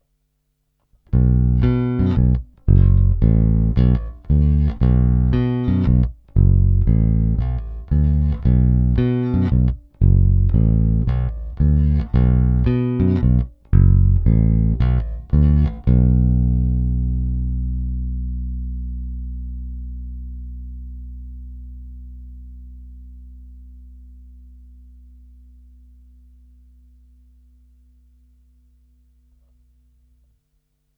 Není-li řečeno jinak, následující nahrávky jsou provedeny rovnou do zvukové karty a jen normalizovány, basy a výšky na nástroji nastavené skoro naplno.
Snímač u krku